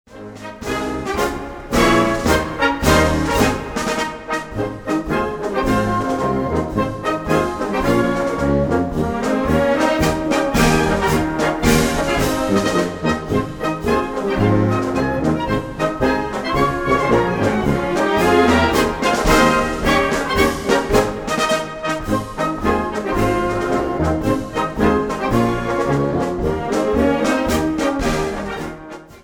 Catégorie Harmonie/Fanfare/Brass-band
Sous-catégorie Marches de défilés
Instrumentation Ha (orchestre d'harmonie)